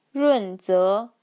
Let’s investigate how well speech recognition does, provided that the input is standardised Mandarin and clearly enunciated by a native teacher.
t1-run4ze2.mp3